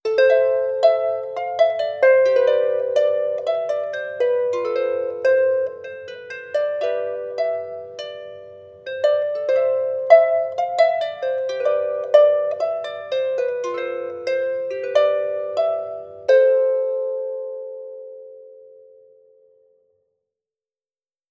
C (C Major - 8B) Free sound effects and audio clips
• celtic harp improvisation.wav
celtic_harp_improvisation_hvh.wav